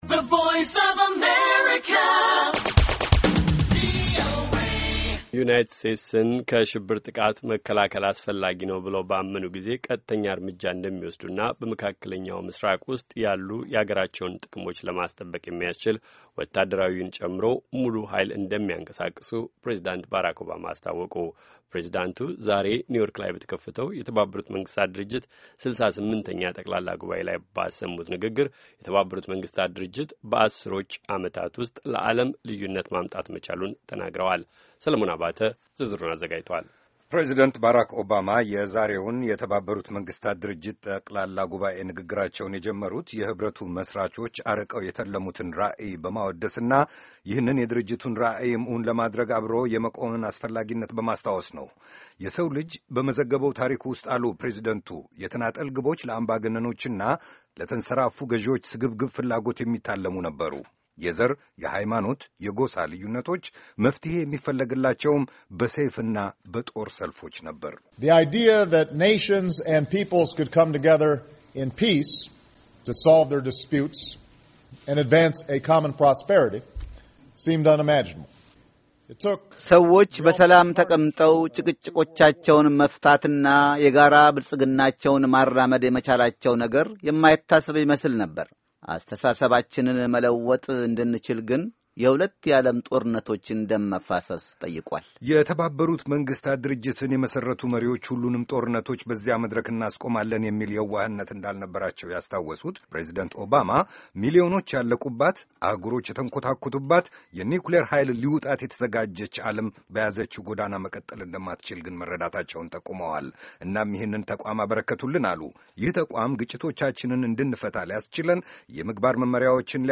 President Obama, UNGA, speech